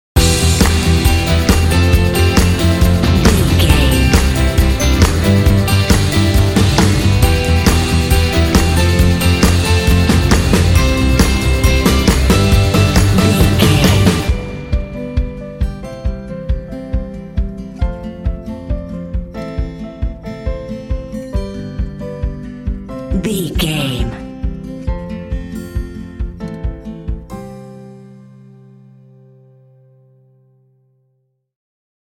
Fun and cheerful indie track with bells and “hey” shots.
Uplifting
Ionian/Major
cheerful/happy
playful
acoustic guitar
electric guitar
bass guitar
drums
piano
indie
alternative rock